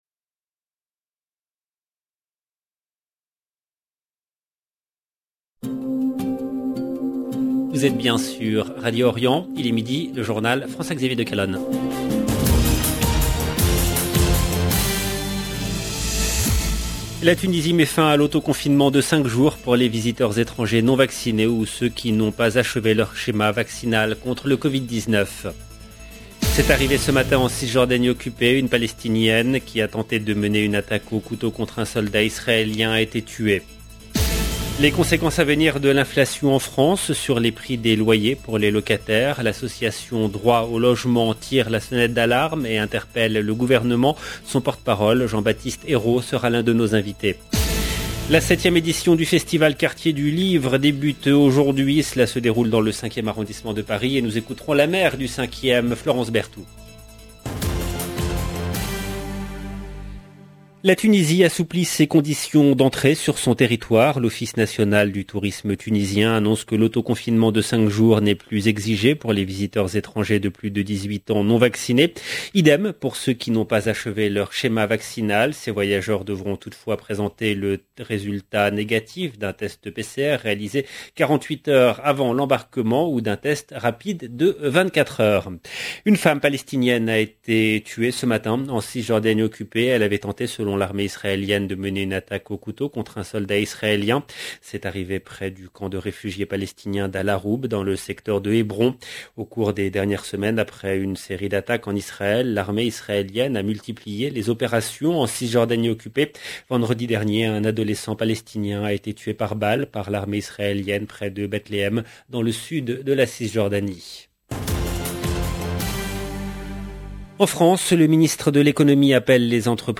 LB JOURNAL EN LANGUE FRANÇAISE
Nous écouterons la maire Florence Berthout. 0:00 16 min 12 sec